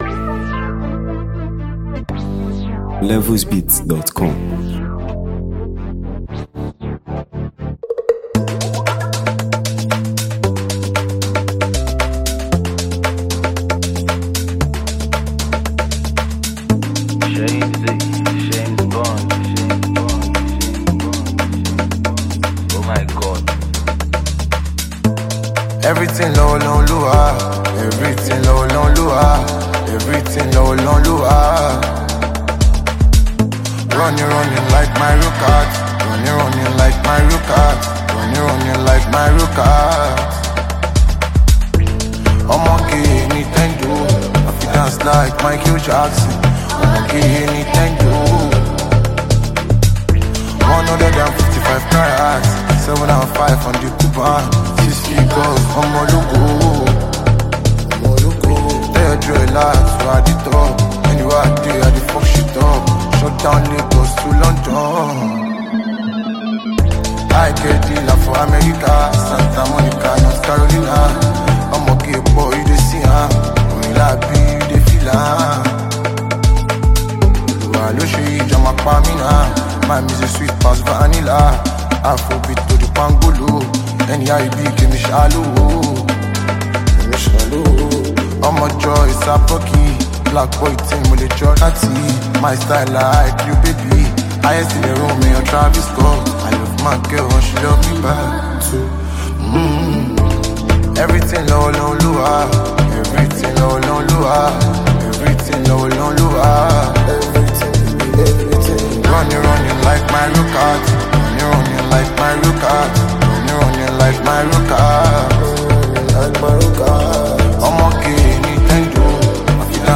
the phenomenally gifted Nigerian singer and songwriter
Known for his emotional depth and street-inspired melodies
With its infectious beats, smooth flows
raw, passionate, and musically unstoppable